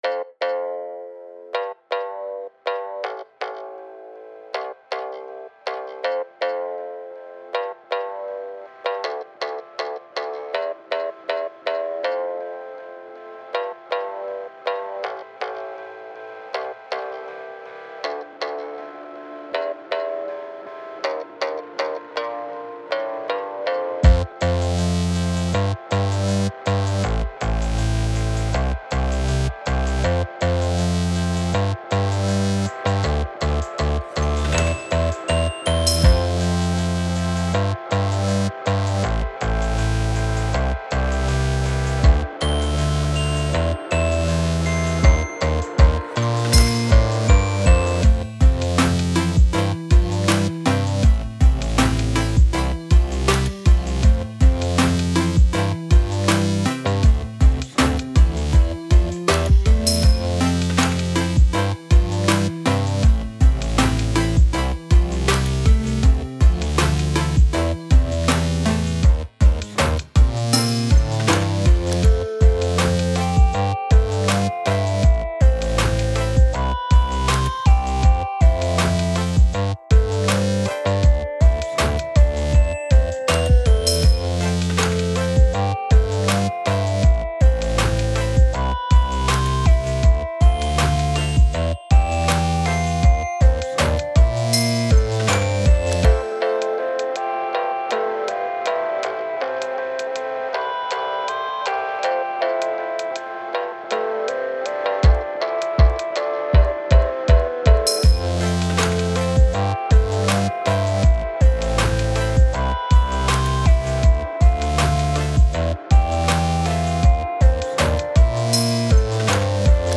Music / Game Music
edm electronica lofi lofi_pop bedroom_pop chill_fi glow_fi chiptune chipmusic
melodic uplifting bouncy